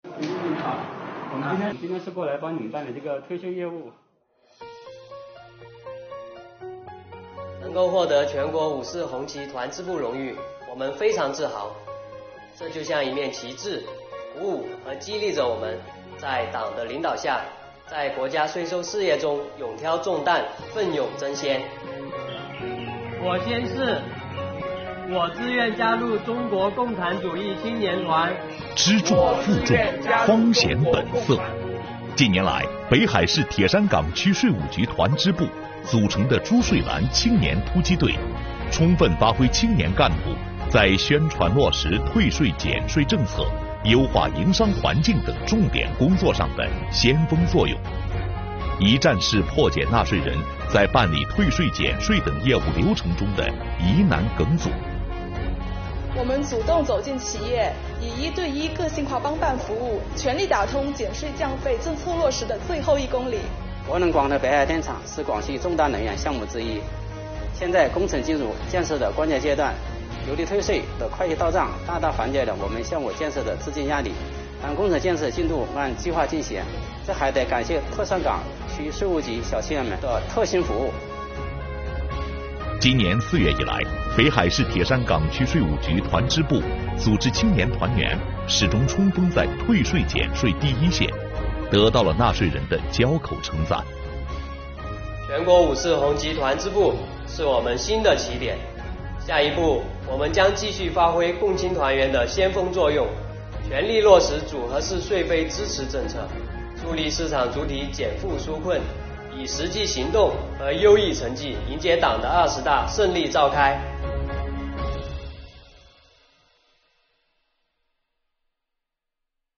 五四青年节到来之际，北海市铁山港区税务局团支部“珠税蓝”青年突击队队员们面向镜头，讲述了他们奋战退税减税一线的故事。